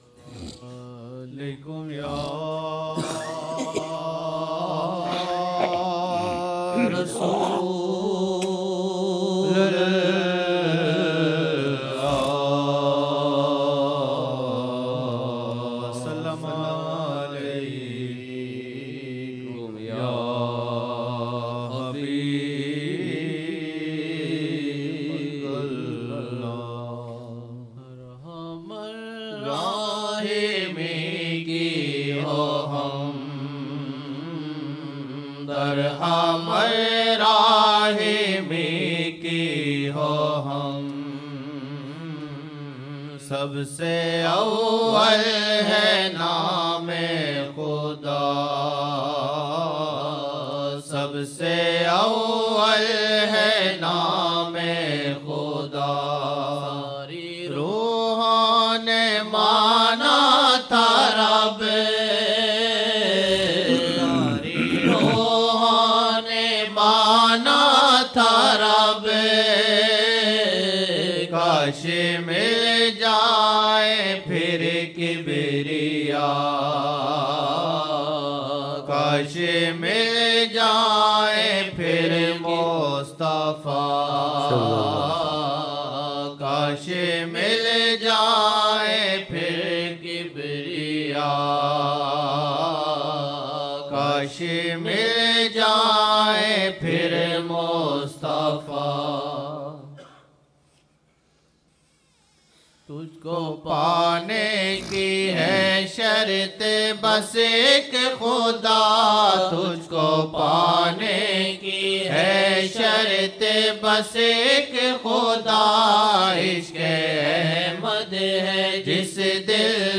Chand Naatia,Hamdia Ashar(Sub Say Awal Hy Nam e Khuda, Tuj ko panay ki hy shart,Ghar Ghulam Un ka banu 2007-01-01 01 Jan 2007 Old Naat Shareef